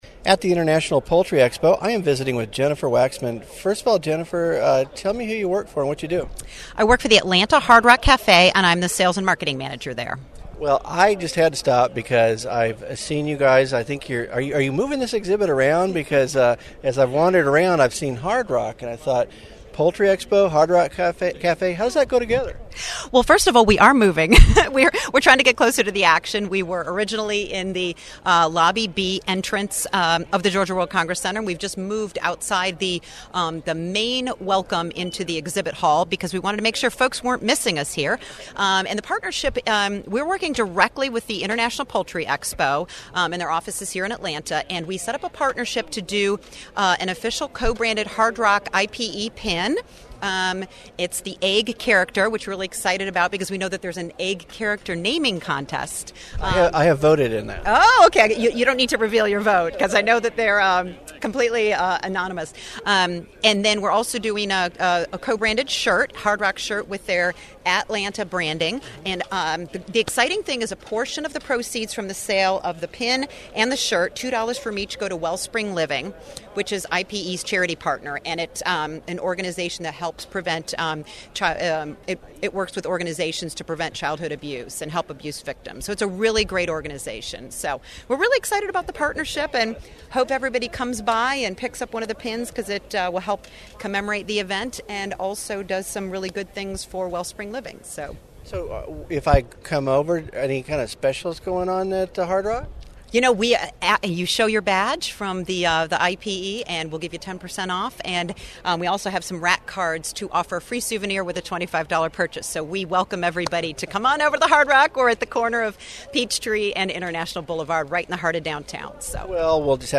Coverage of the 2012 International Poultry Expo is sponsored by Novus International